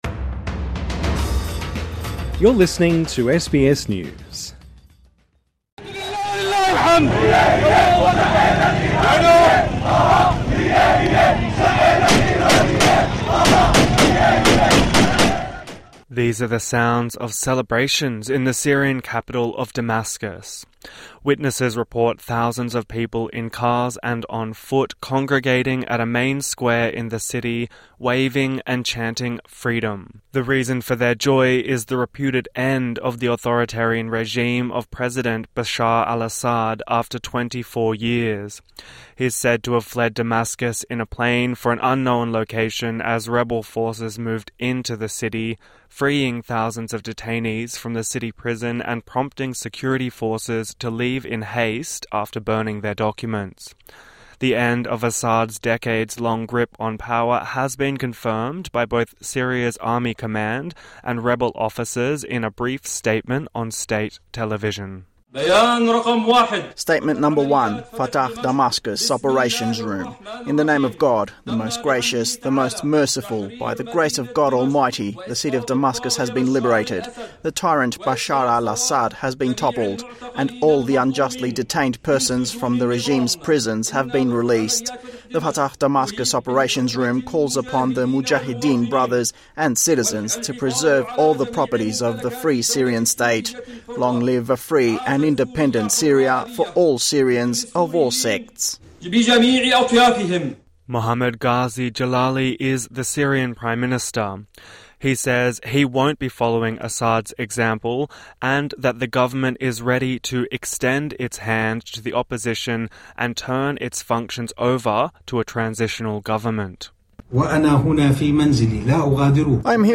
TRANSCRIPT: These are the sounds of celebrations in the Syrian capital of Damascus.